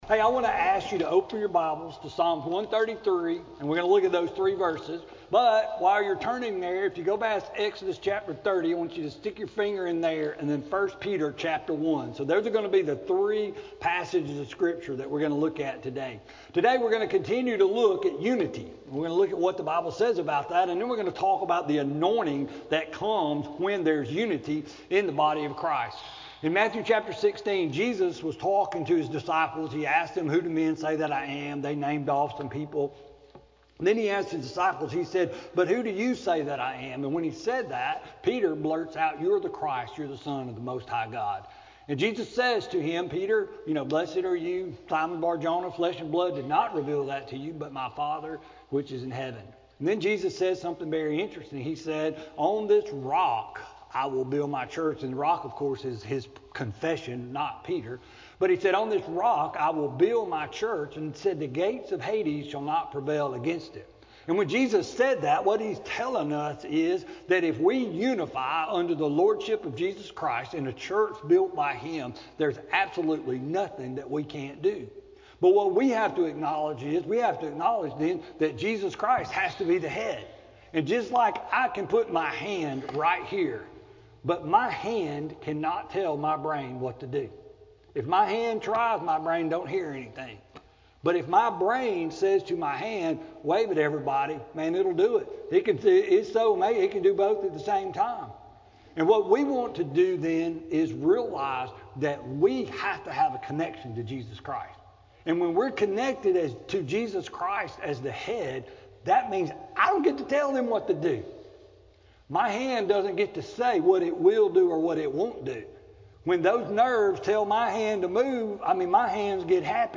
Sermon-3-17-19-CD.mp3